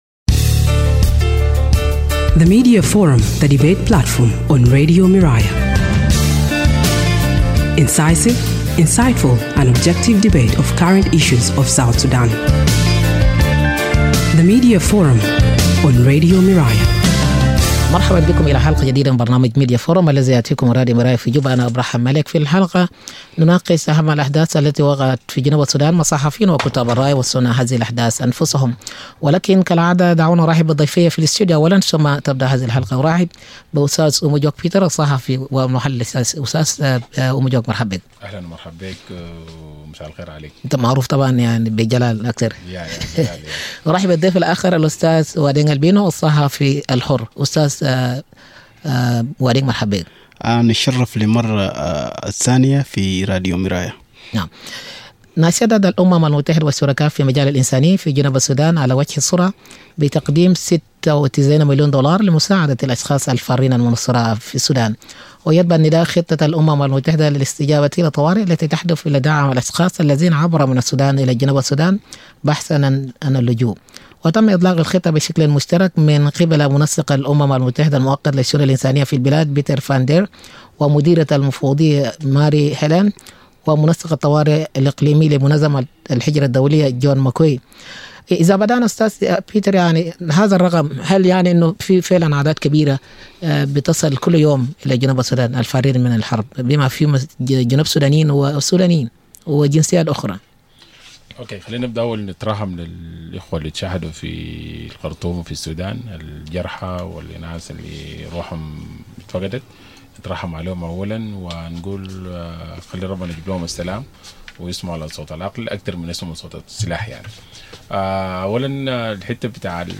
freelancer journalists